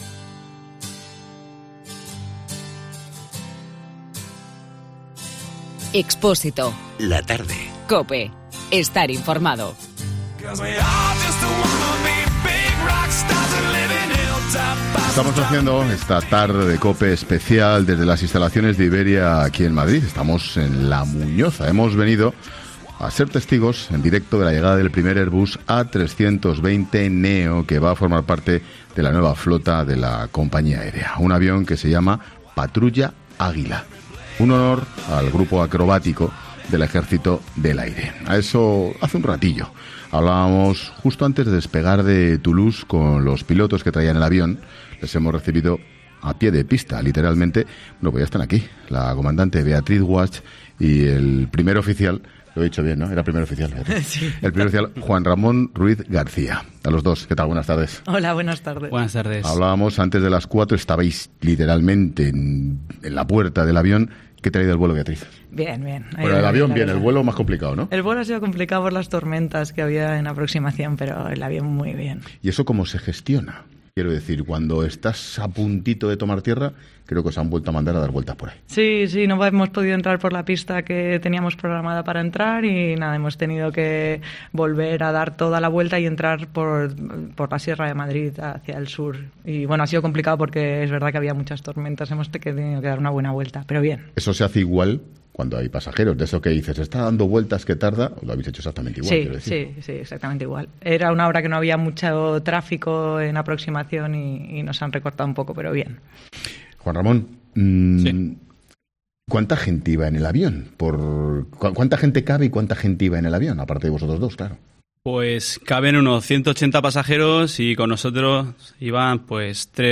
Hablamos en 'La Tarde' con tres de los comandantes de la Patrulla Águila, que da nombre al nuevo avión de Iberia